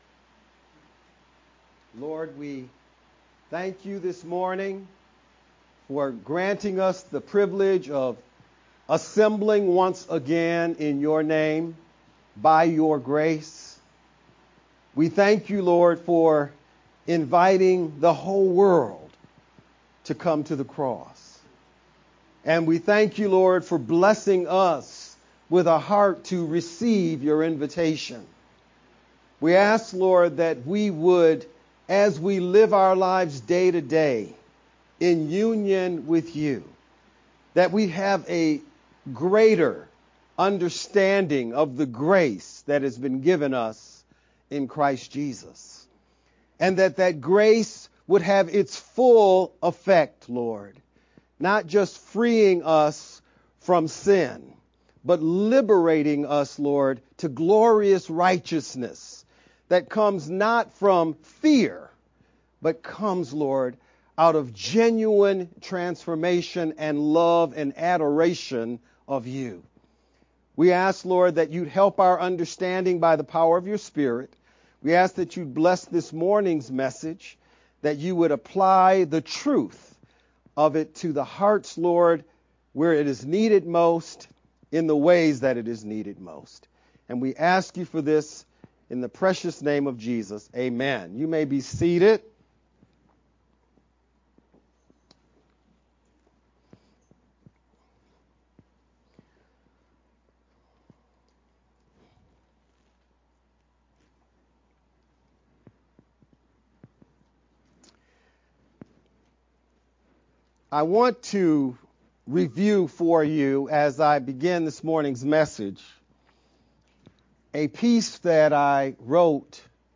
VBCC-Sermon-only-edited_Converted-CD.mp3